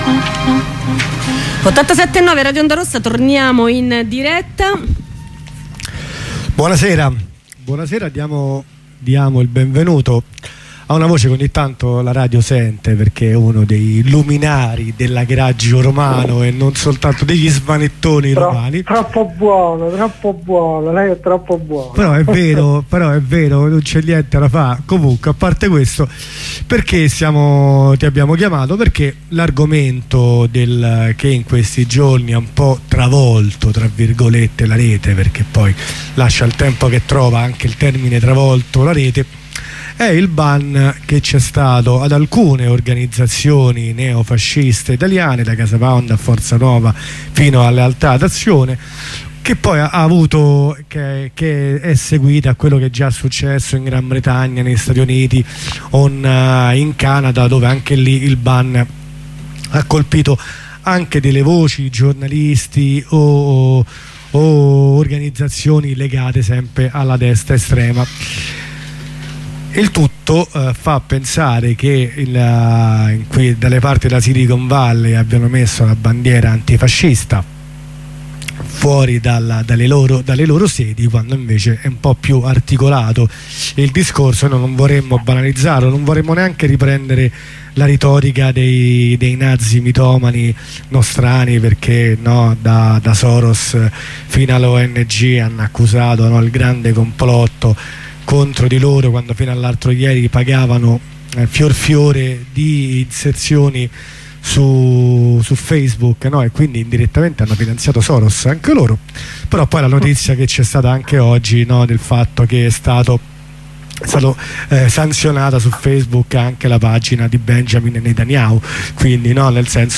Ne parliamo con un compagno.